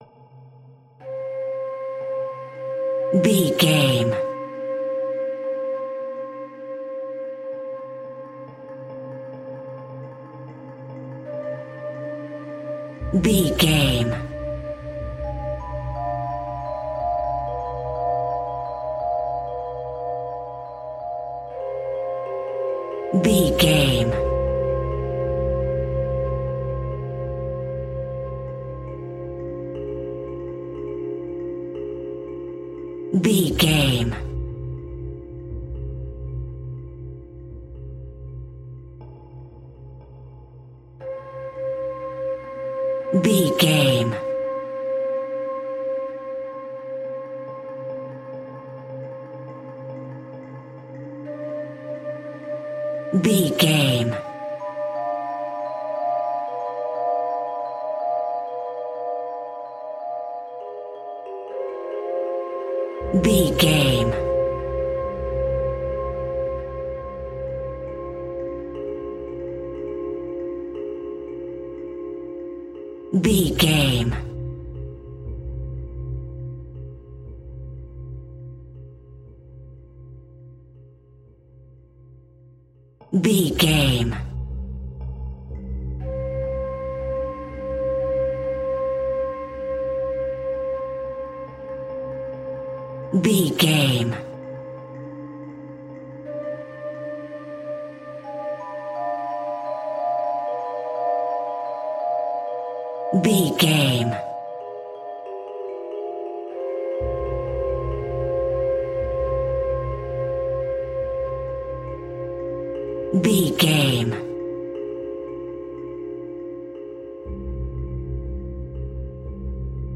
Aeolian/Minor
Slow
ominous
dark
eerie
synthesiser
flute
Horror Pads
Horror Synth Ambience